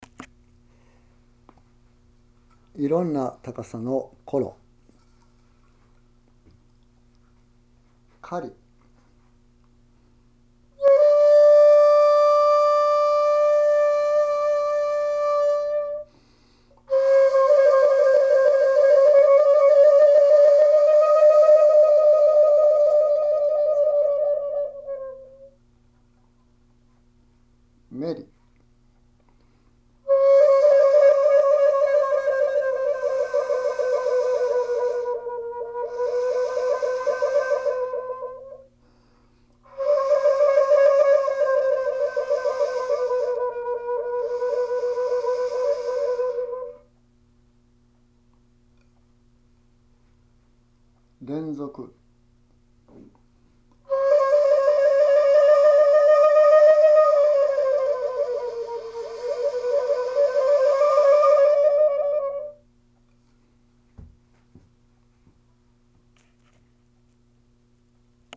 ○次にコロの指使いから五孔（裏）を明けるとカリ気味のコロになります。また四孔と五孔をかざしていきますとメリ気味のコロになります。現代邦楽でよく使われる手法です。